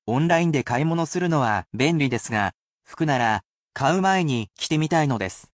The sentences, however, would be a bit much to memorise and are meant to be used as examples rather than reused in their entirety, so these are read aloud at normal speed.
[basic polite speech]